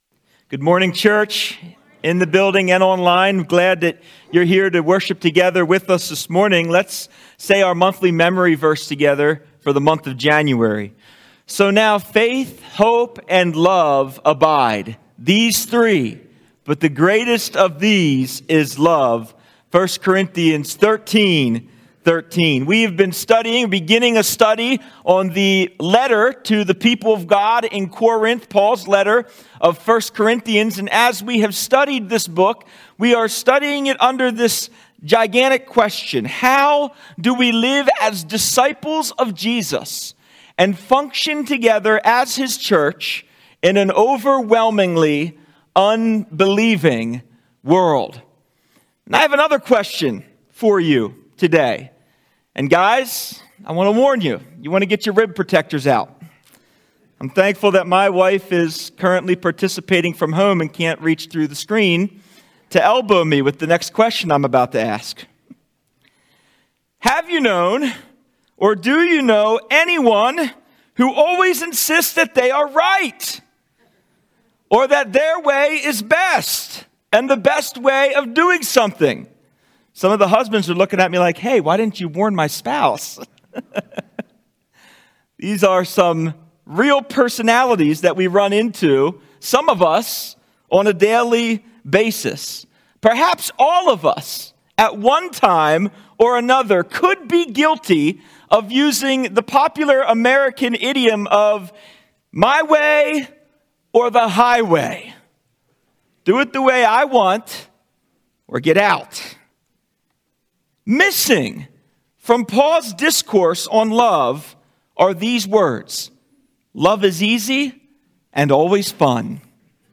1 Corinthians 13:5b-6 Sermon